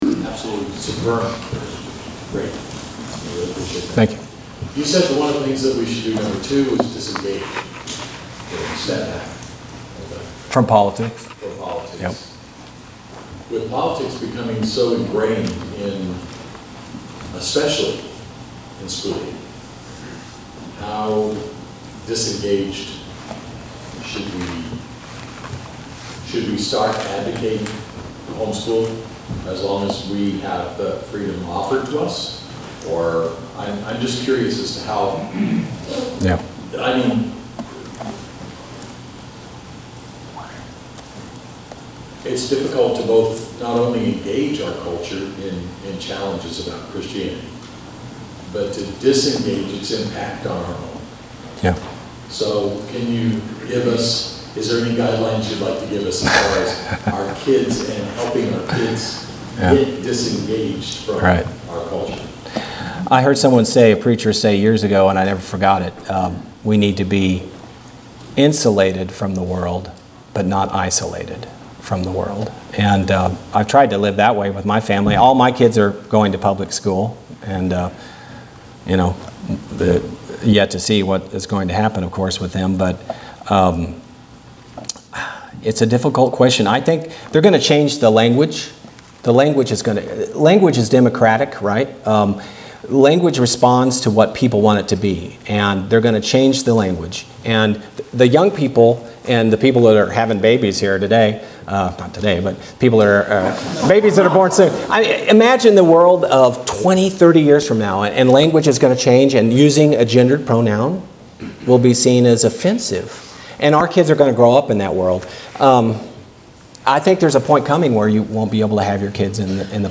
Gender Issues and The Bible Q&A MP3 Link